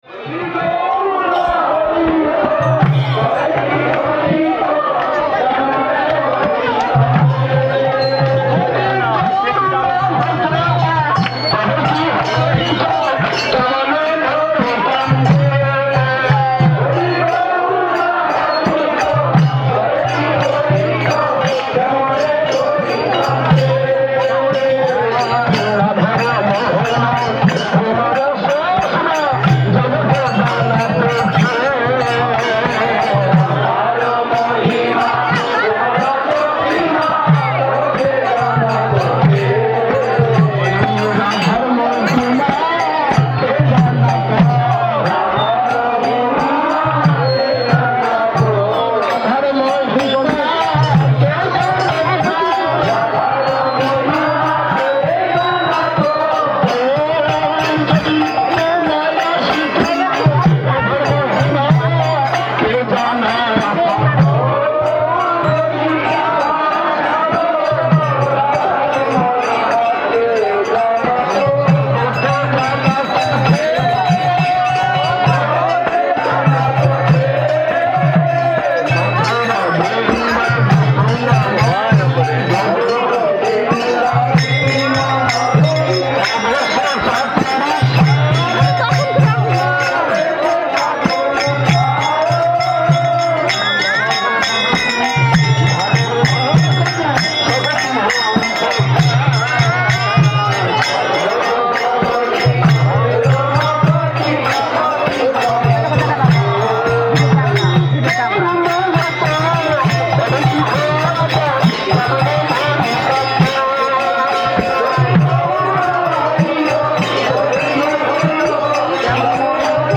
Место: ШЧСМатх Навадвип
Тэги: Киртан